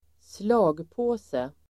Ladda ner uttalet
Uttal: [²sl'a:gpå:se]